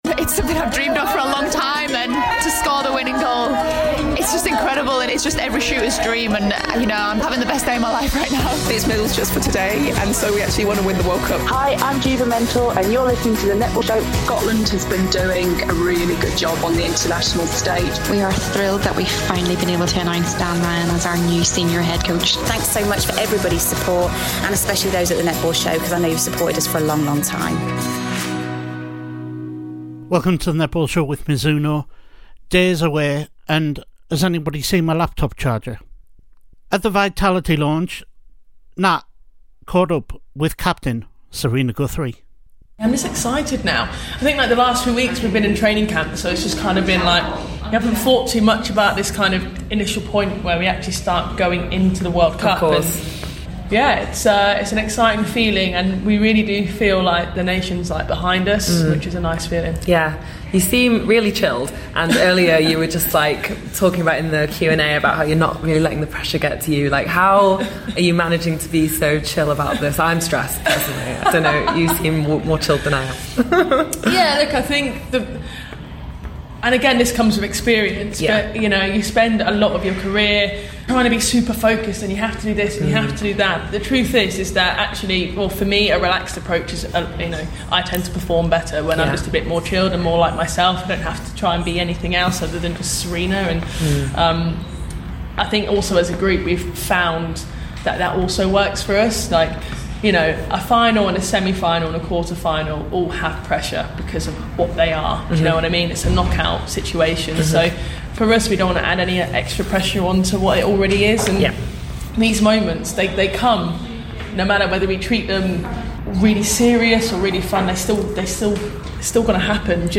at the Vitality Netball World Cup launch